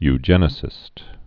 (y-jĕnĭ-sĭst) also eu·gen·ist (yjə-nĭst)